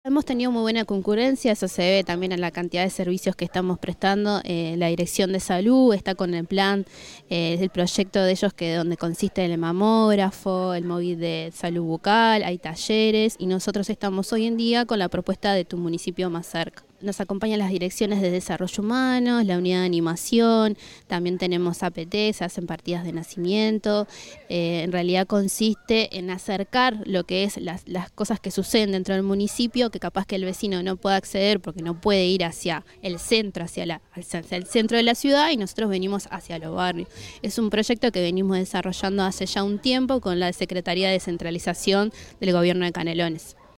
La alcaldesa interina del Municipio de Las Piedras, Romina Espiga, dijo en los jardines del castillo que "Hemos tenido gran concurrencia, eso se ve en la cantidad de servicios que estamos prestando, la Dirección de Salud está con el Programa de Salud, nosotros estamos con la propuesta Tu municipio + Cerca, nos acompañan las direcciones de Desarrollo Humano, la Unidad de Animación, se hacen partidas de nacimiento.